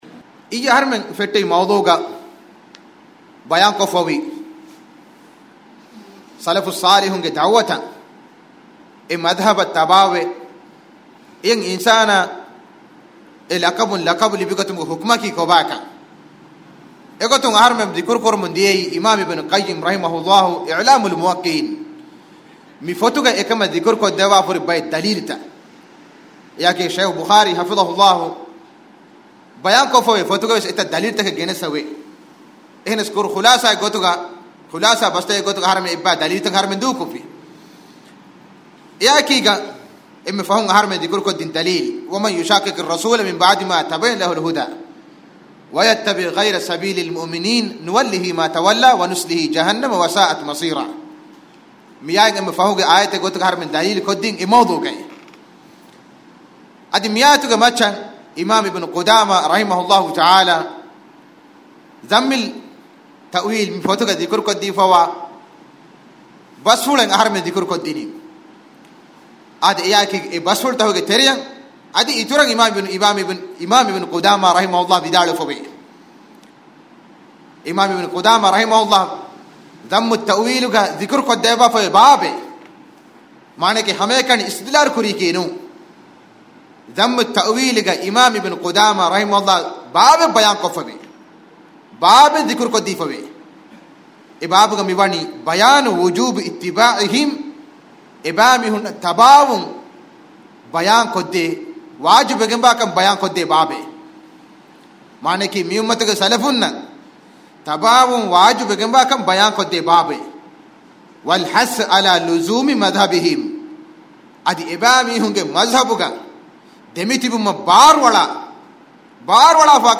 1435 ވީ ރޯދަ މަހު މަކްތަބާ ސަލަފިއްޔާގައި ސަލަފިއްޔަތަކީ ކޮބައިކަން ބަޔާންކޮށް ދިން ދަރުސްތަކެއް